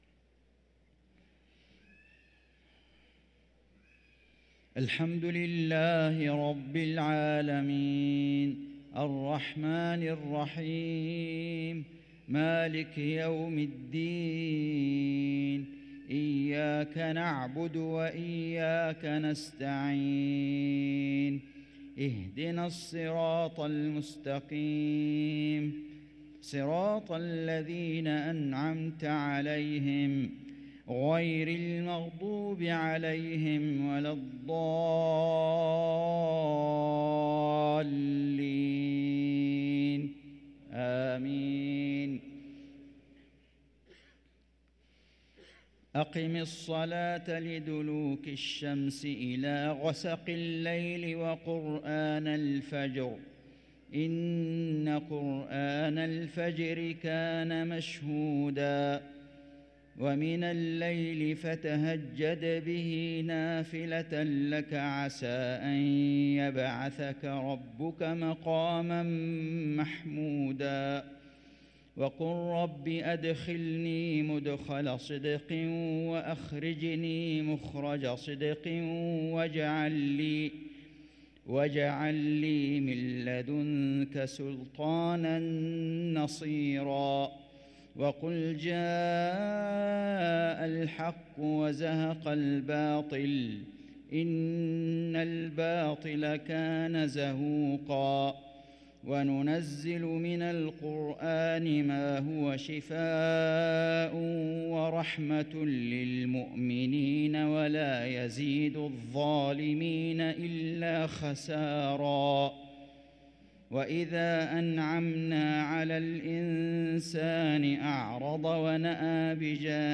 صلاة العشاء للقارئ فيصل غزاوي 25 شعبان 1444 هـ